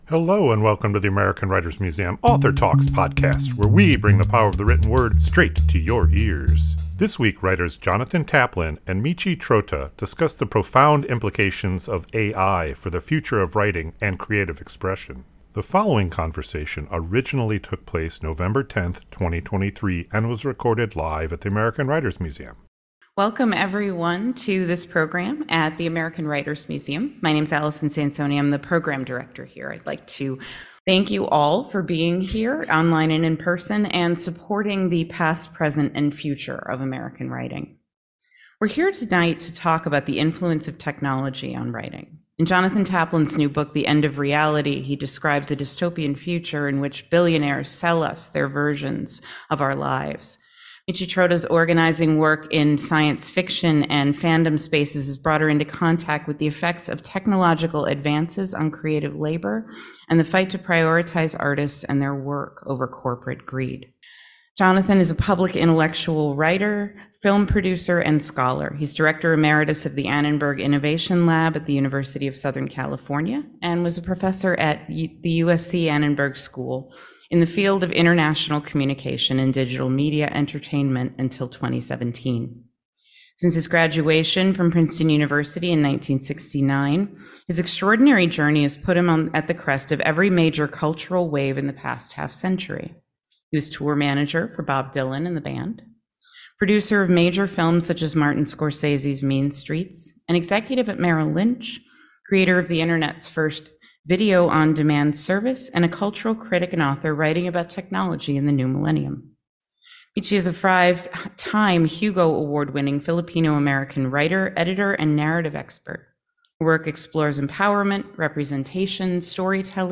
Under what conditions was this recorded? This conversation originally took place November 10, 2023 and was recorded live at the American Writers Museum.